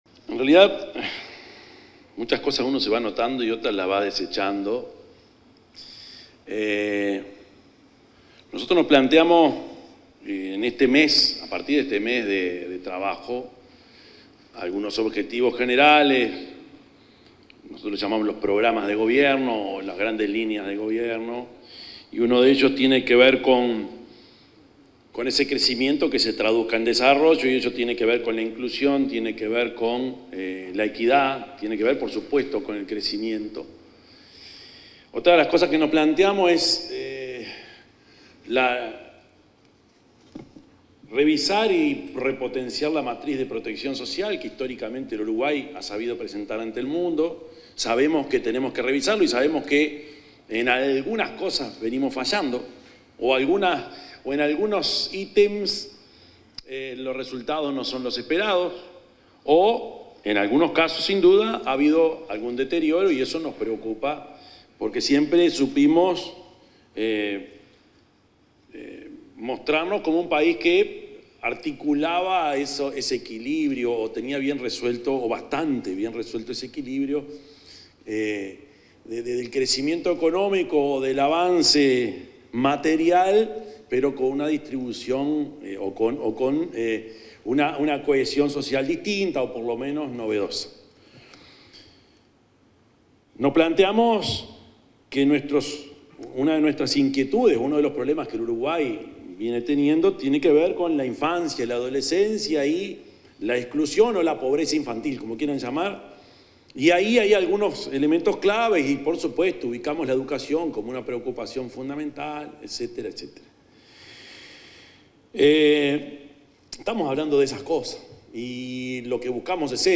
Palabras del presidente Yamandú Orsi en lanzamiento de Laboratorio de Inteligencia Artificial
En el marco del lanzamiento del Laboratorio de Inteligencia Artificial aplicada a la Educación, con Ceibal, como centro de innovación educativa con